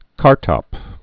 (kärtŏp)